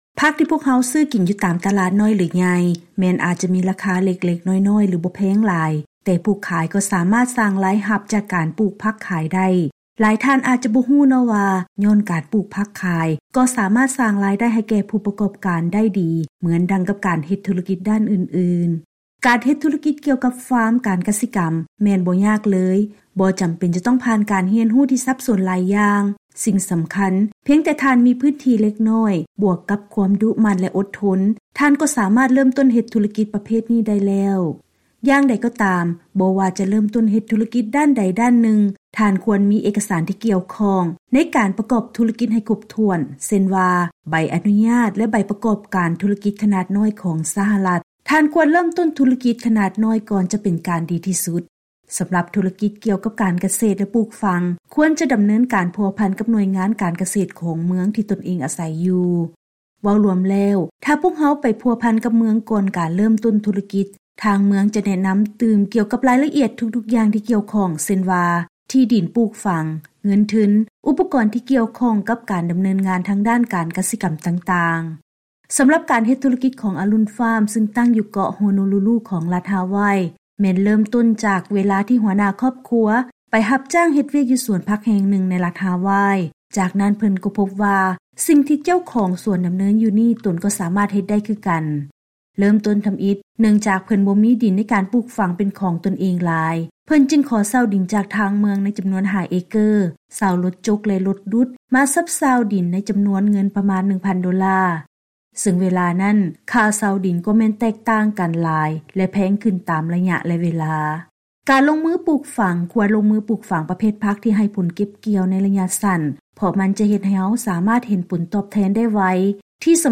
ເຊີນຮັບຟັງລາຍງານກ່ຽວກັບ ການເຮັດທຸລະກິດໃນດ້ານການກະເສດ.